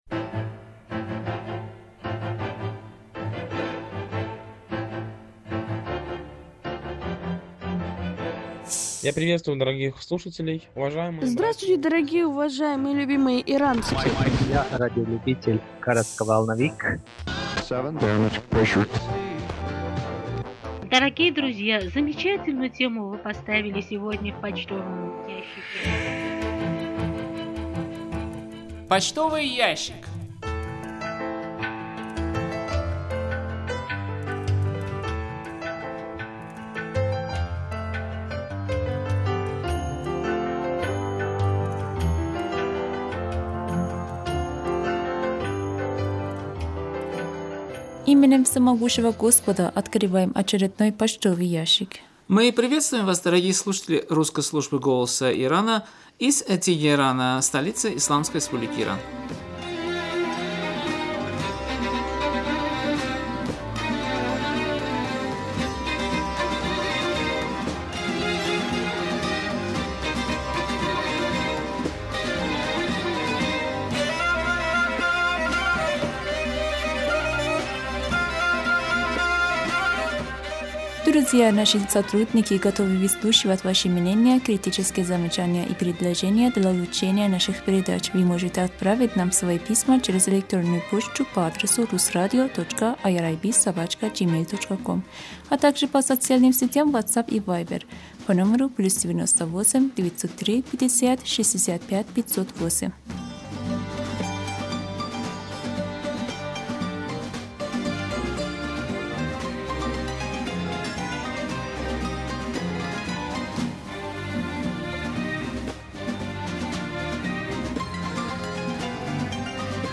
Беседа: Беседа с имам-хатыбом мечети им.